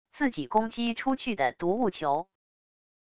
自己攻击出去的毒雾球wav音频